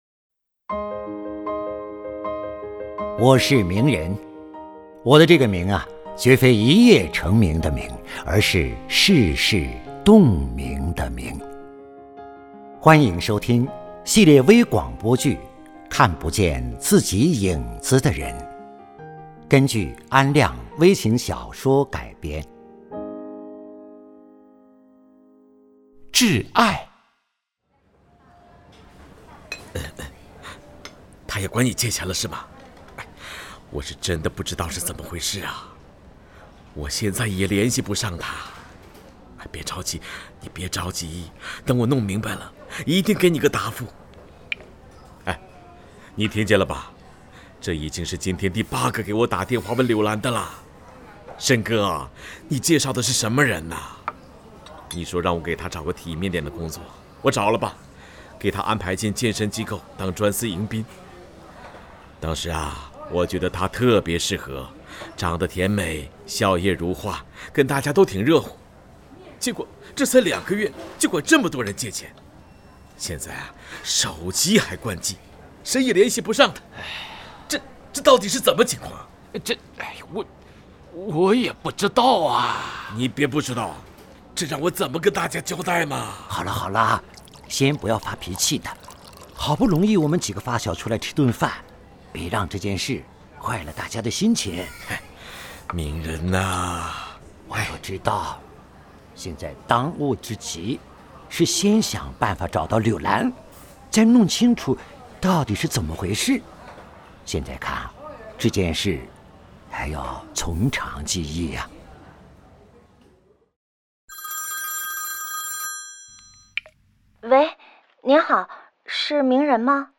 • 广播类型：微广播剧